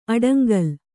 ♪ aḍaŋgal